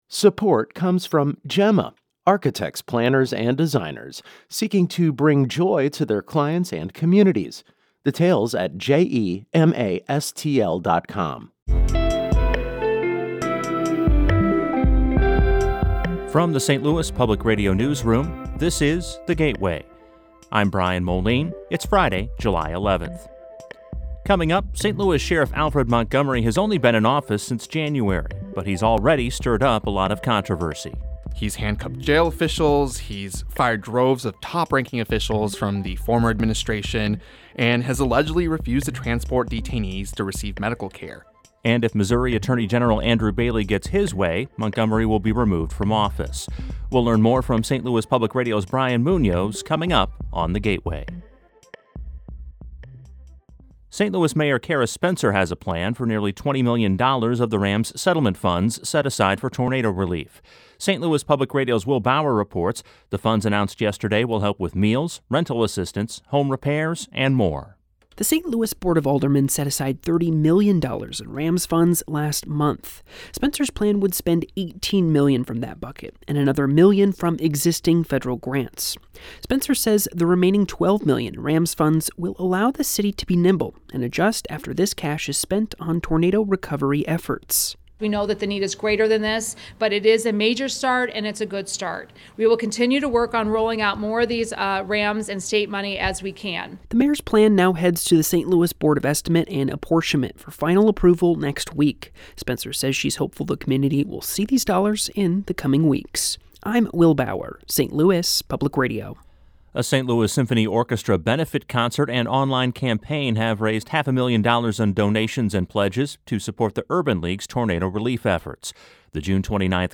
Essential news for the St. Louis region. Every weekday, in about 8 to 10 minutes, you can learn about the top stories of the day, while also hearing longer stories that bring context and humanity to the issues and ideas that affect life in the region.